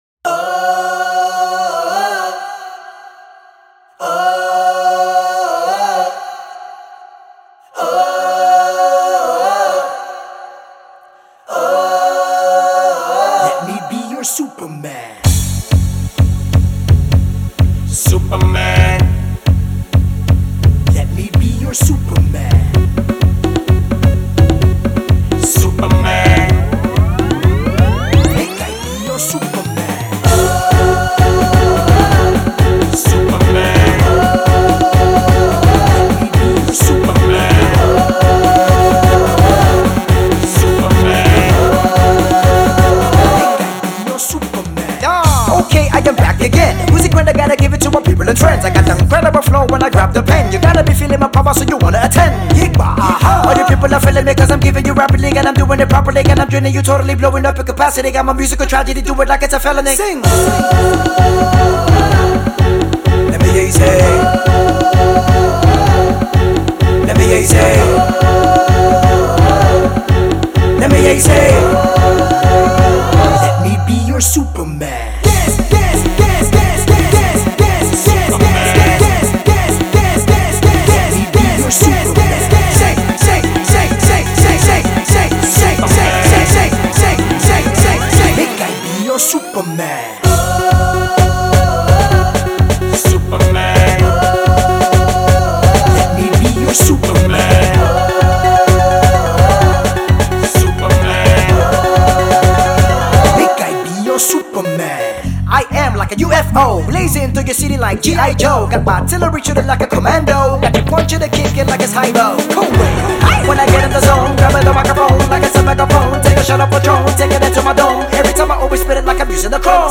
Nigeria’s fast-spitting rapper
something a little unconventional